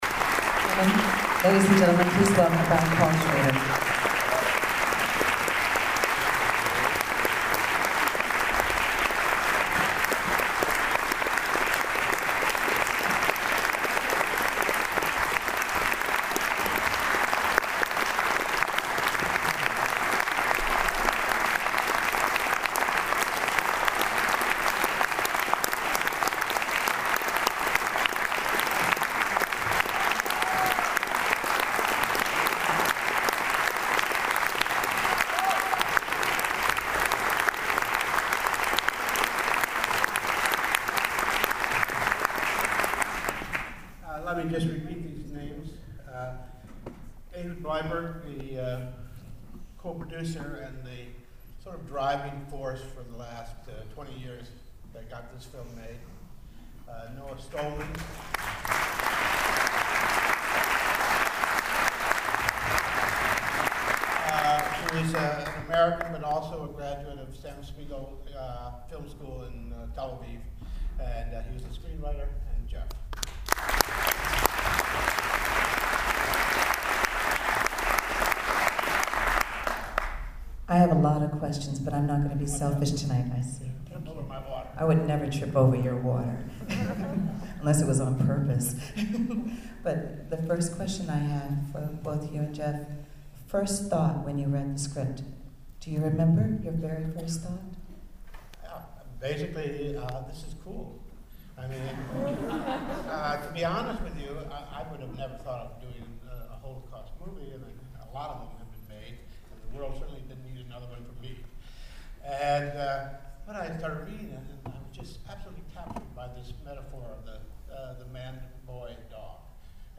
adamresurrected_qa.mp3